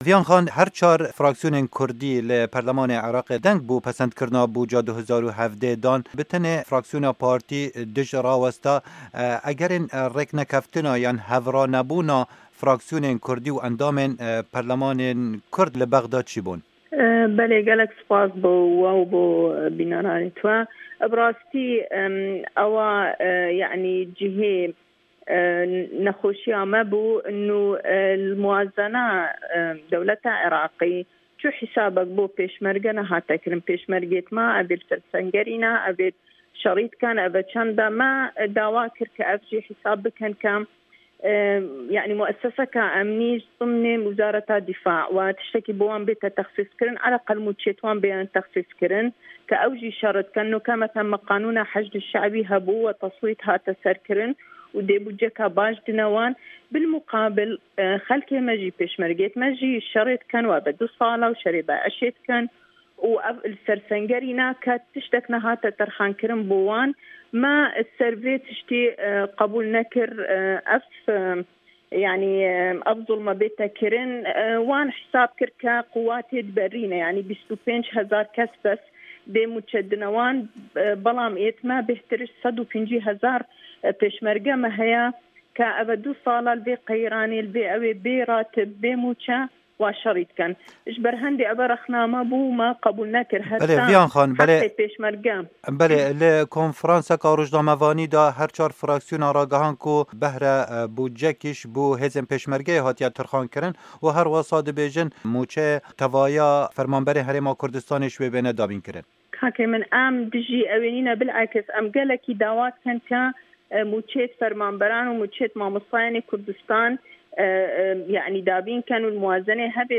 Hevpeyvîn li gel Viyan Dexîl endama Parlamena Îraqê